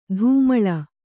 ♪ dhūmaḷa